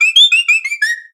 Cri de Plumeline Style Buyō dans Pokémon Soleil et Lune.
Cri_0741_Buyō_SL.ogg